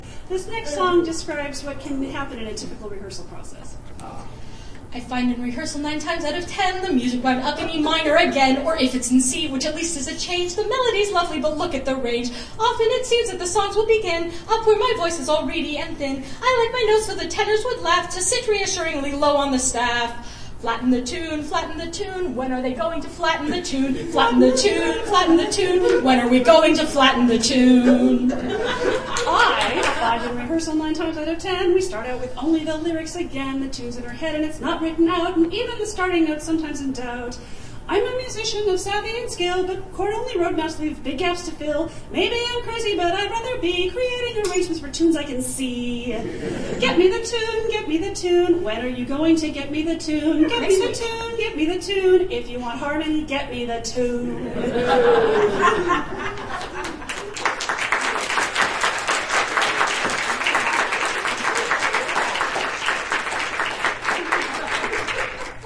This concert was performed at Consonance 2007